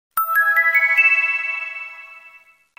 positive-ring_24705.mp3